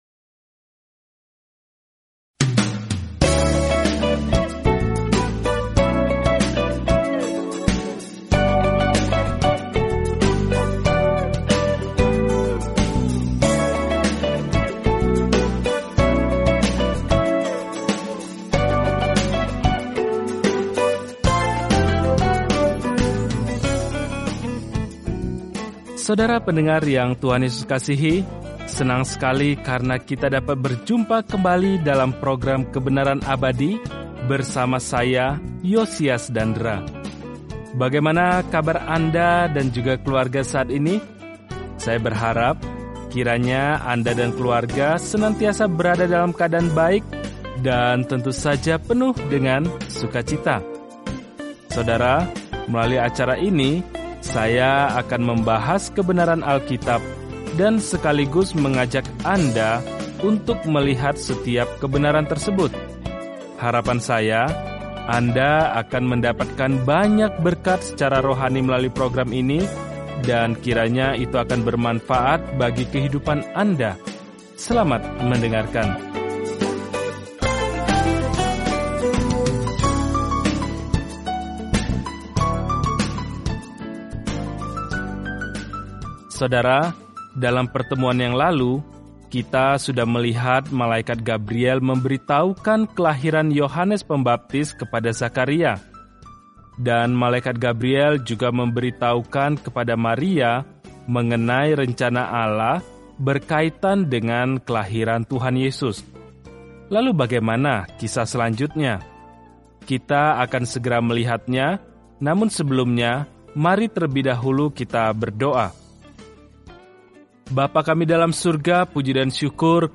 Firman Tuhan, Alkitab Lukas 1:36-80 Hari 2 Mulai Rencana ini Hari 4 Tentang Rencana ini Para saksi mata menginformasikan kabar baik yang diceritakan Lukas tentang kisah Yesus sejak lahir, mati, hingga kebangkitan; Lukas juga menceritakan kembali ajaran-Nya yang mengubah dunia. Telusuri Lukas setiap hari sambil mendengarkan pelajaran audio dan membaca ayat-ayat tertentu dari firman Tuhan.